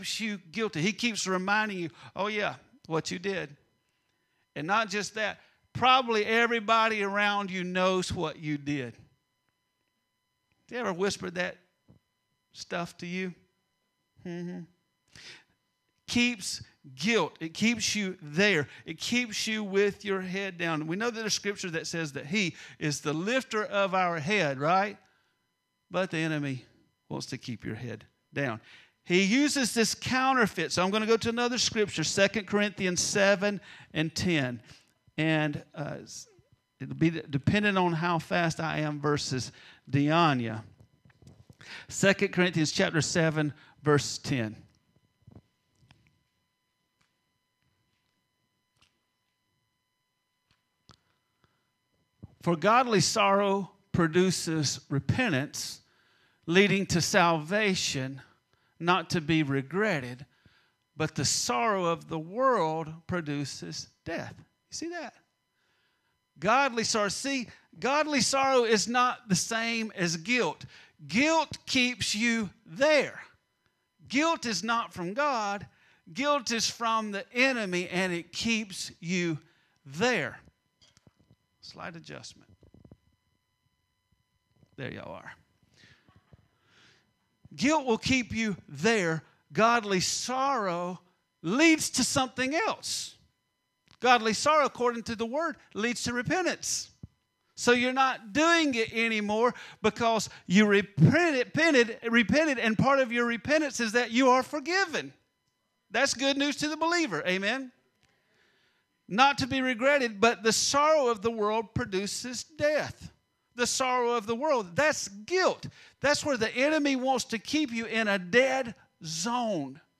There is no shame in Christ (Easter Service)